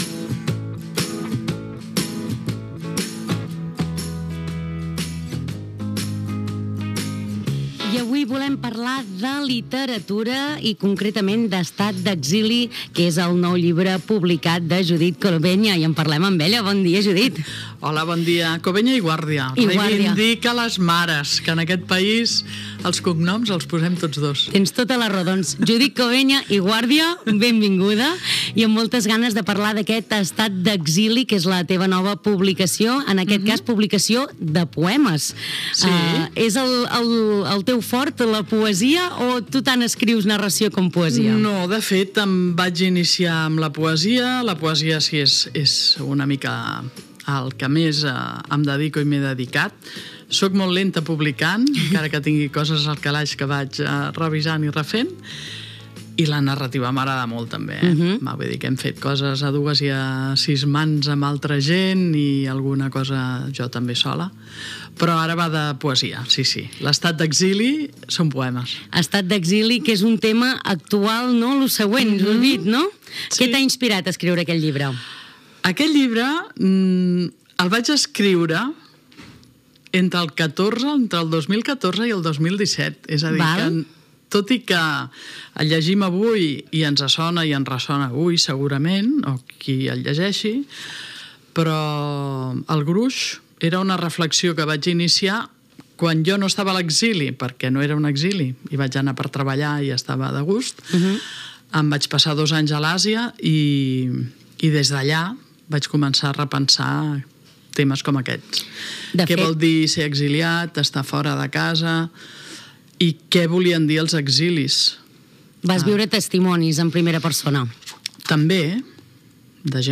Entrevista
FM